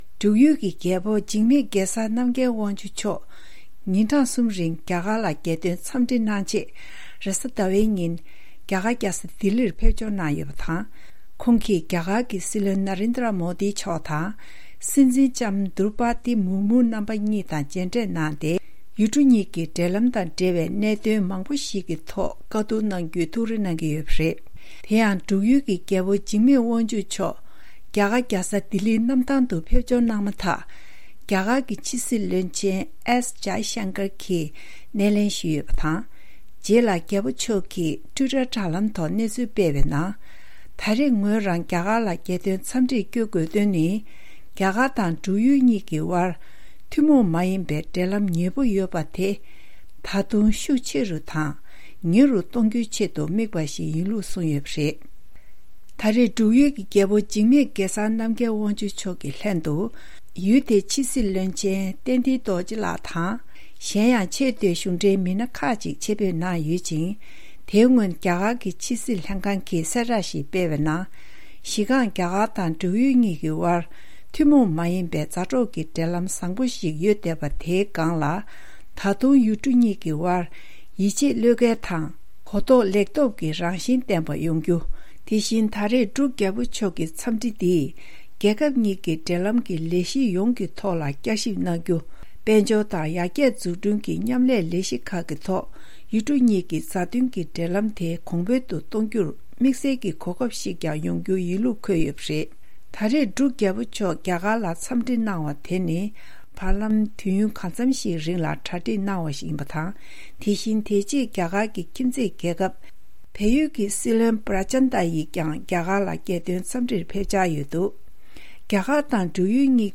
ཕབ་བསྒྱུར་དང་སྙན་སྒྲོན་ཞུས་གནང་བ་འདིར་གསལ།